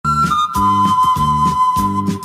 알림음(효과음) + 벨소리
알림음 8_Condor.mp3